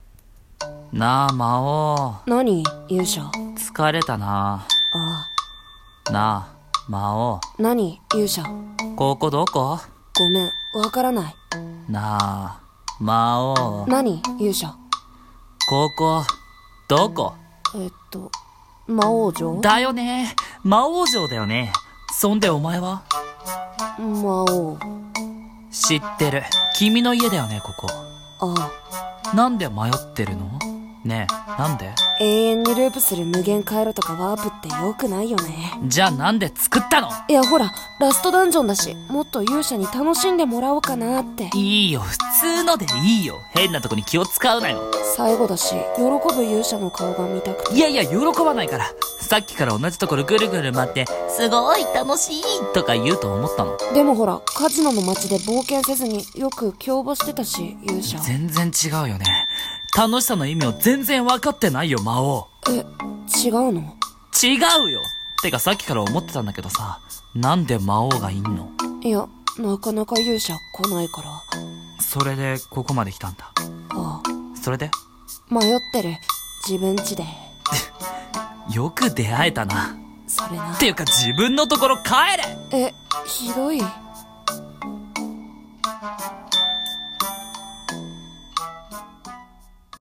声劇「ラストダンジョンにて」